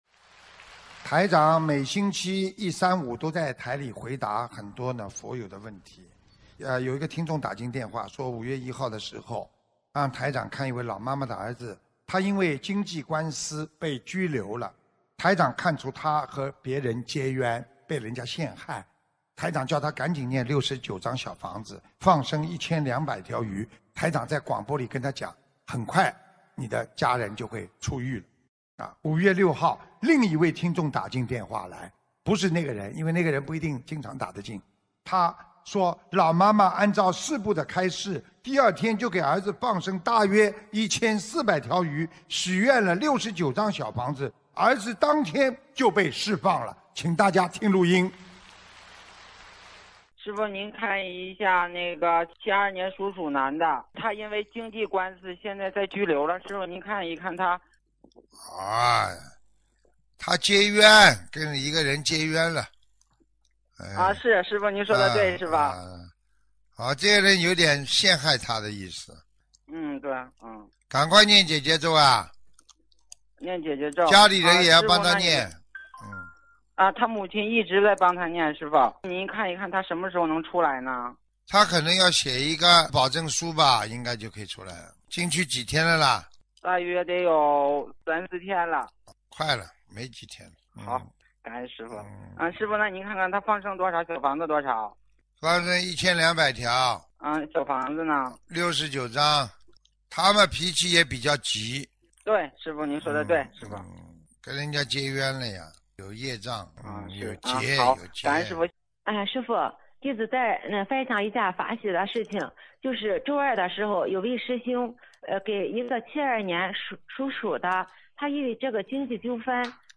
音频：【因为冤结被人陷害被拘留，运用心灵法门三大法宝后第二天就释放了】2018年10月14日美国纽约法会精彩节目